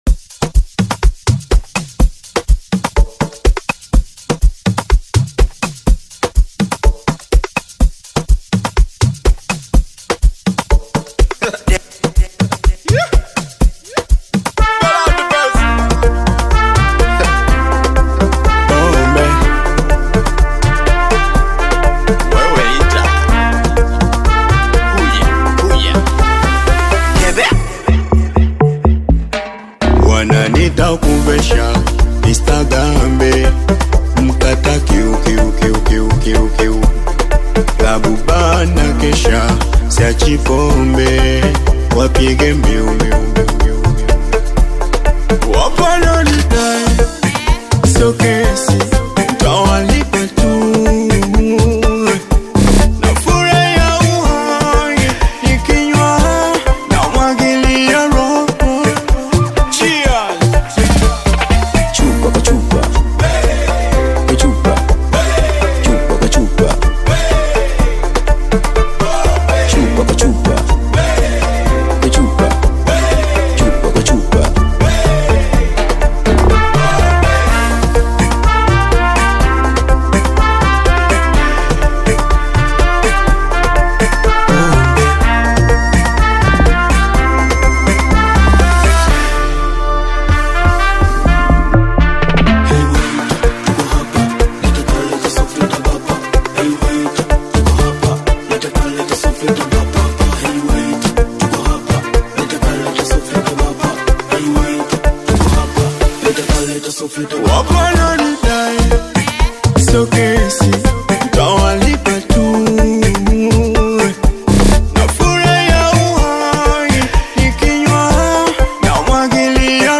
Tanzanian bongo flava artist singer
African Music